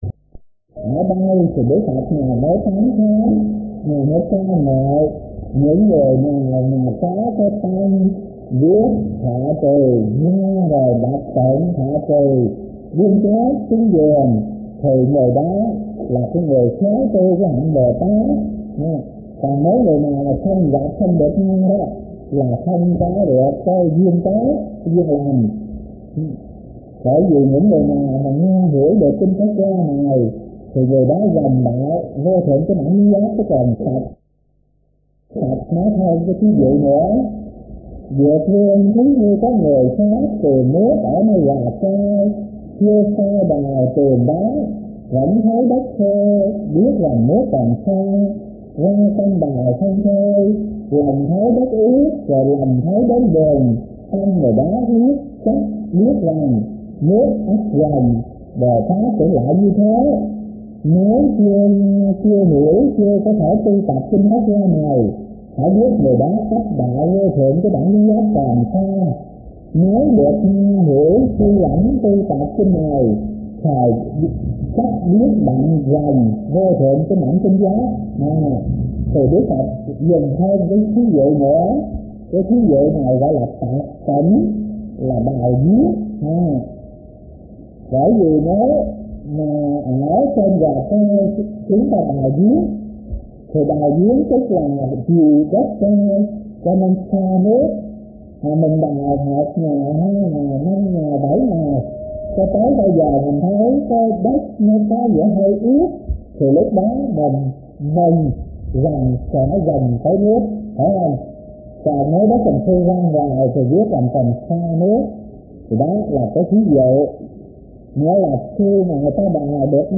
Kinh Giảng Kinh Pháp Hoa - Thích Thanh Từ